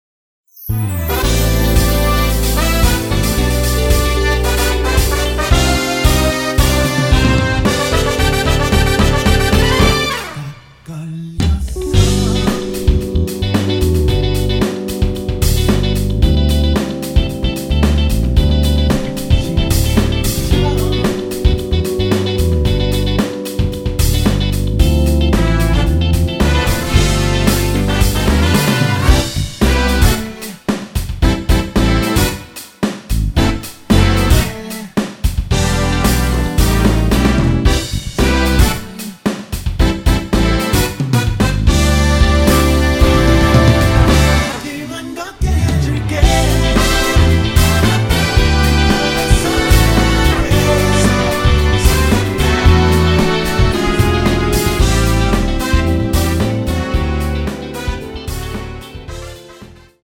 코러스 잘 들어가있는 깔끔한 MR입니다!
앞부분30초, 뒷부분30초씩 편집해서 올려 드리고 있습니다.
곡명 옆 (-1)은 반음 내림, (+1)은 반음 올림 입니다.